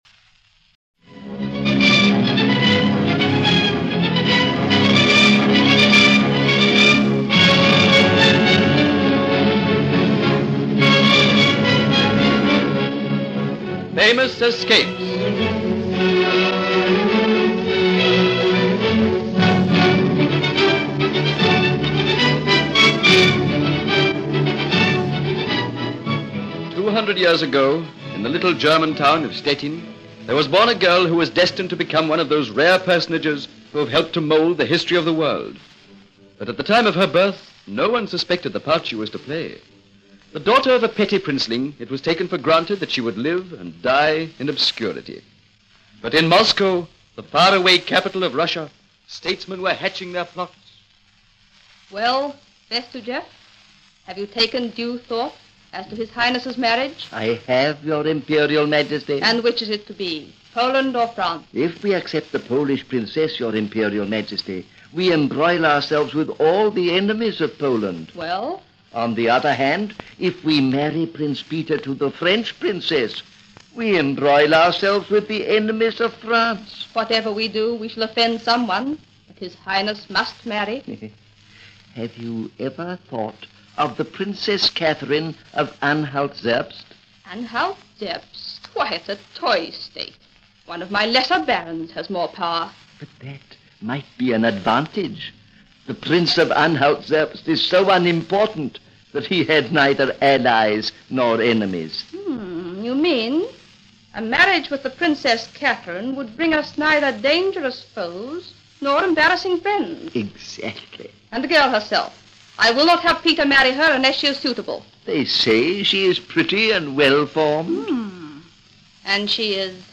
Famous Escapes was a captivating radio series produced in Australia around 1945.